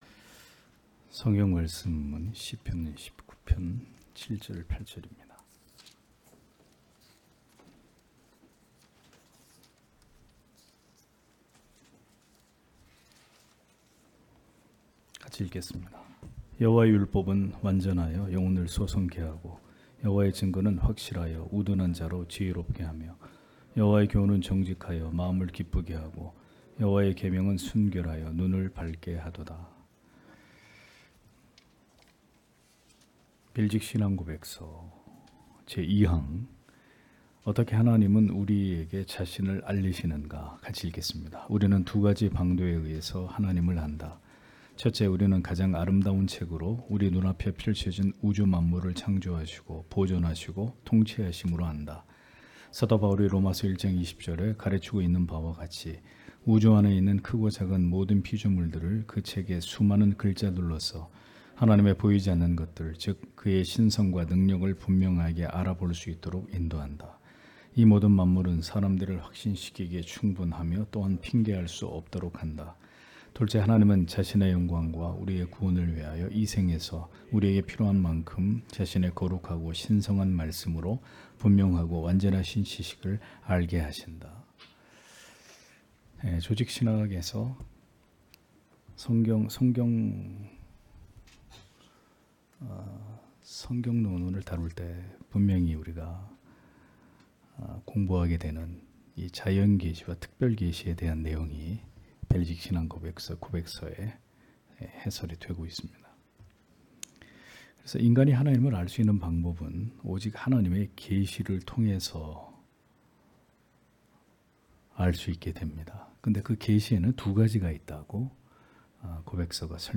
주일오후예배 - [벨직 신앙고백서 해설 2] 제2항 어떻게 하나님은 우리에게 자신을 알리시는가 (시편 19장 7-8절)
* 설교 파일을 다운 받으시려면 아래 설교 제목을 클릭해서 다운 받으시면 됩니다.